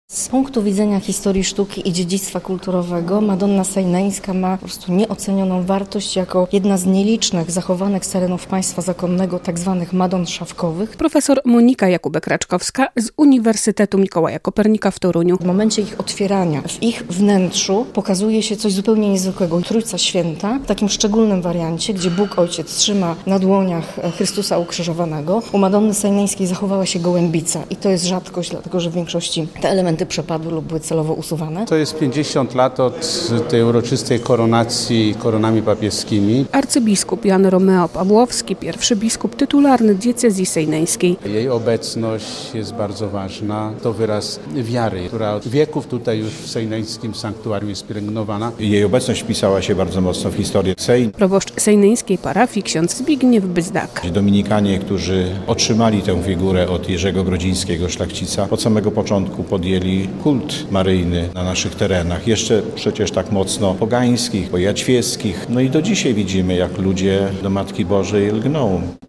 50-lecie koronacji Matki Boskiej Sejneńskiej - relacja